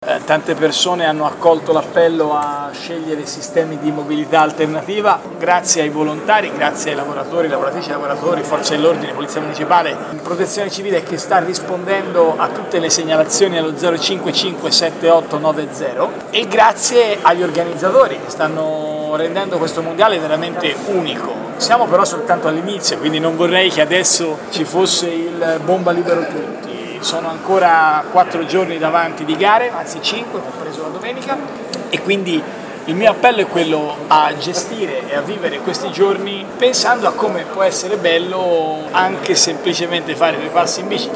A loro dico grazie".Lo ha detto a Firenze, a margine dell'inaugurazione di una mostra sulla bicicletta nell'ambito dei Mondiali di ciclismo, il sindaco Matteo Renzi.